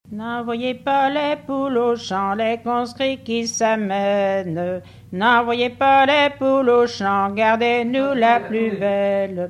chant de conscrits
Chants brefs - Conscription
Pièce musicale inédite